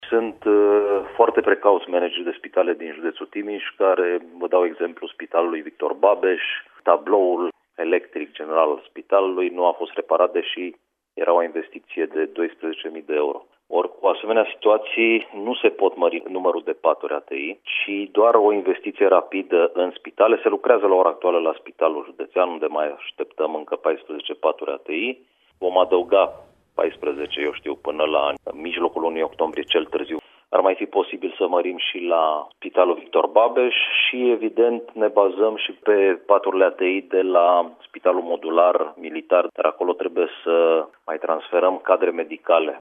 Numărul paturilor ATI destinate pacienților COVID va crește, în Timiș, până cel târziu la mijlocul lunii viitoare, a anunțat, la Radio Timișoara, subprefectul Ovidiu Drăgănescu.